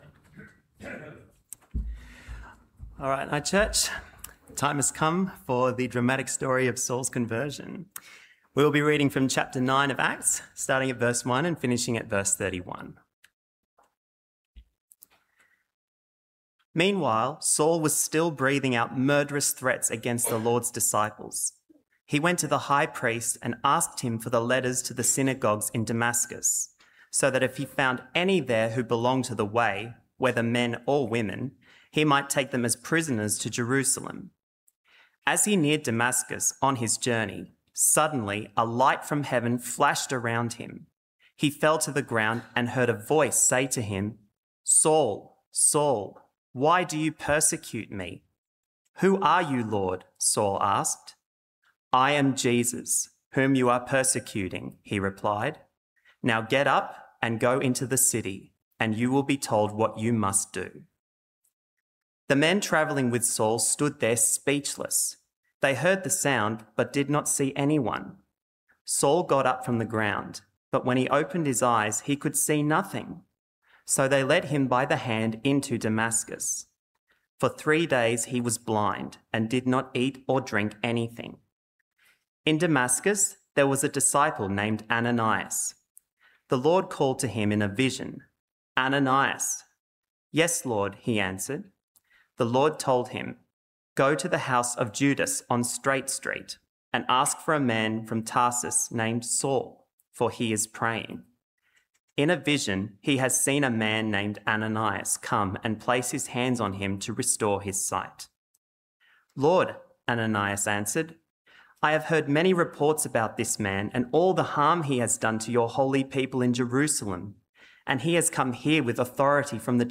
Sermons – Cairns Presbyterian Church